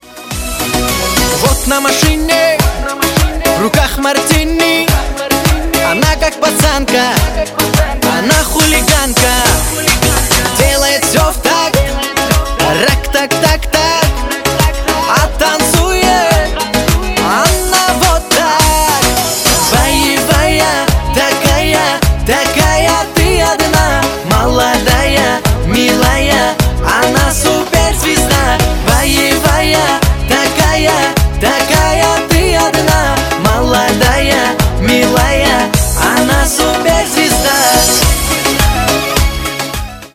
• Качество: 320, Stereo
позитивные
заводные